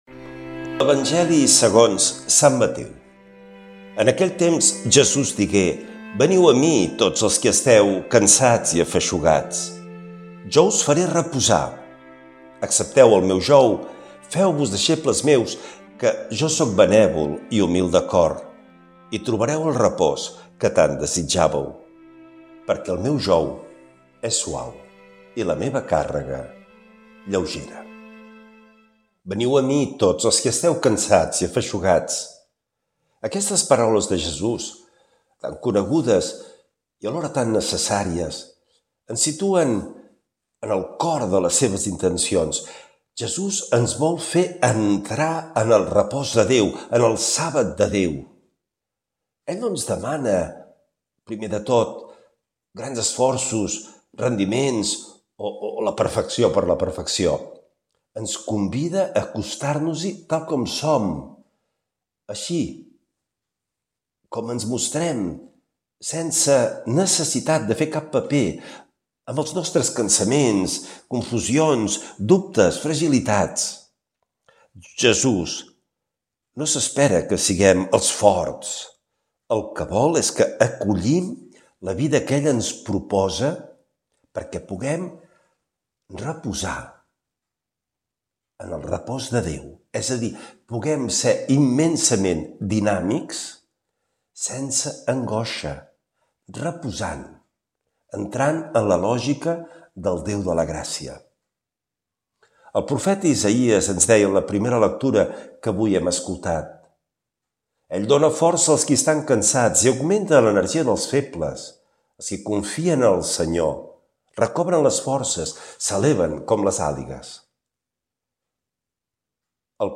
Lectura de l’evangeli segons sant Mateu